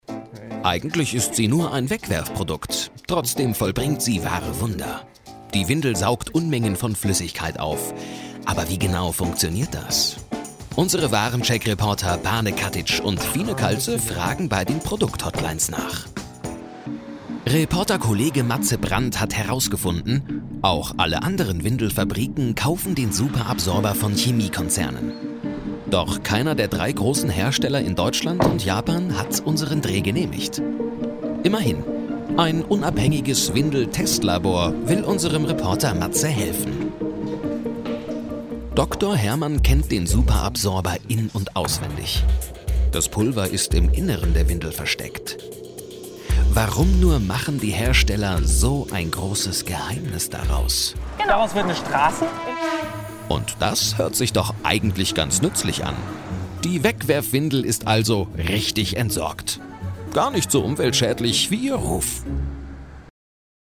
Wandelbare, freundliche und angenehme Männerstimme mittleren Alters.
Sprechprobe: Sonstiges (Muttersprache):